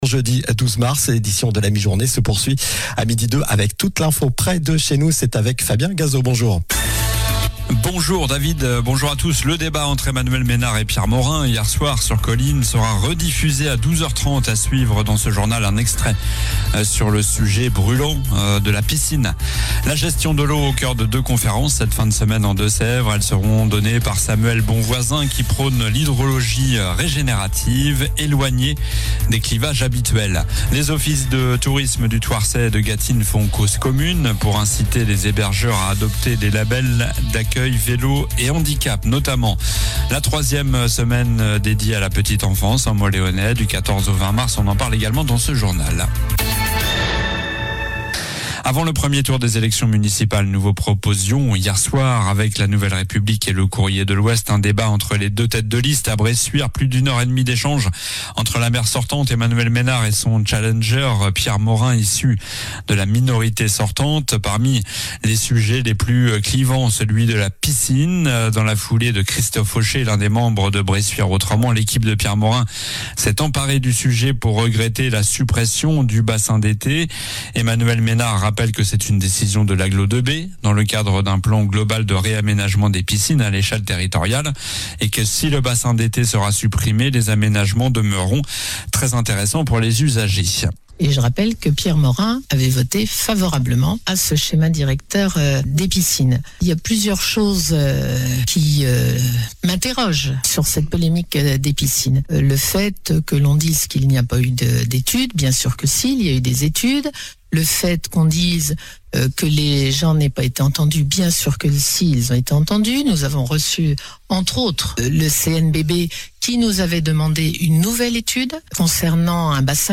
Journal du jeudi 12 mars (midi)